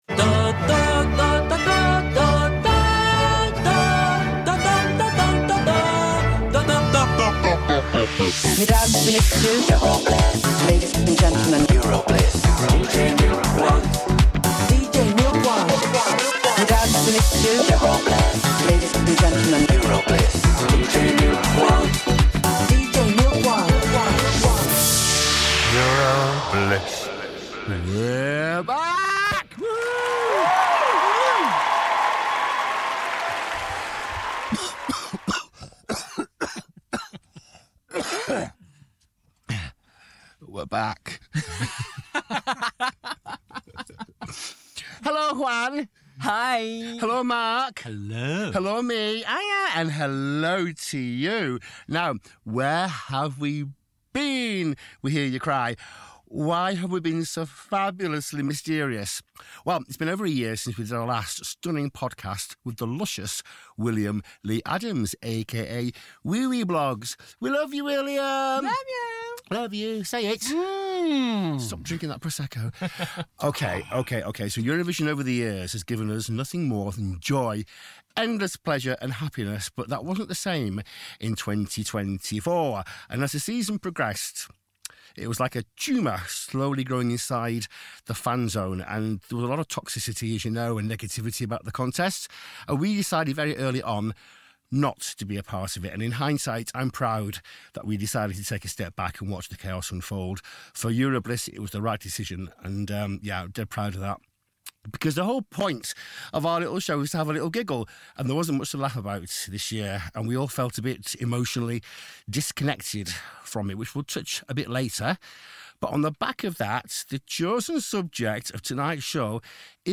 Each month Eurobliss unearths forgotten classics and will take you for a trip down amnesia lane to remind you of a huge fistful of songs you seriously thought, and in some cases hoped, you would never ever hear again. There is always plenty of drama and lots of controversy, interesting facts and, above all, loads of laughs as your hosts guzzle prosecco and politely disagree with each other.